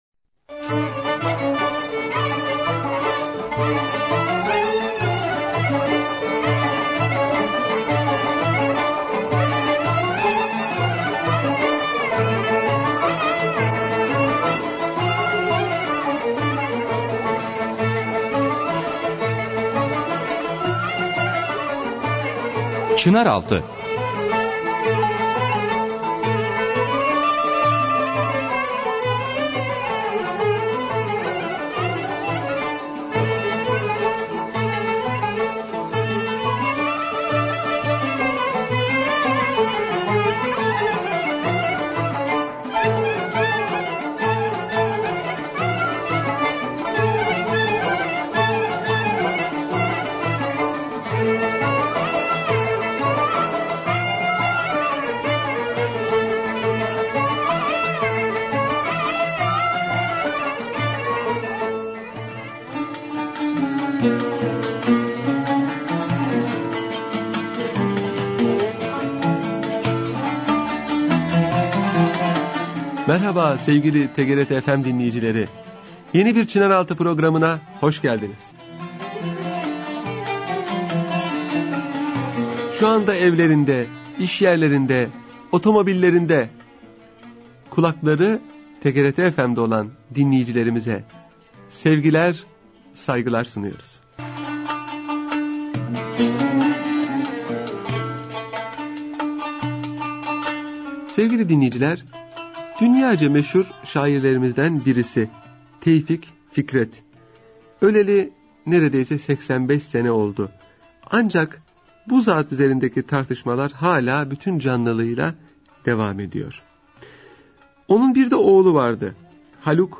Radyo Programi - Tevfik Fikret ve Oğlu